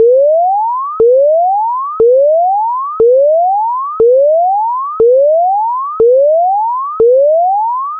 警用警报器决赛
描述：一个警察警笛就像我在大胆玩耍时发出的声音。